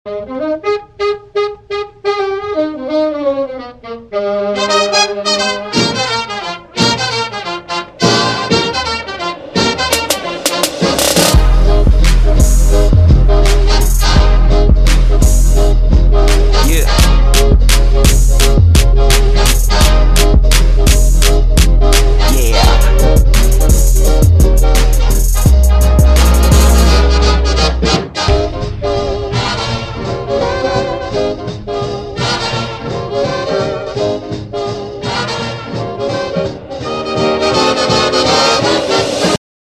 • Качество: 128, Stereo
Trap
труба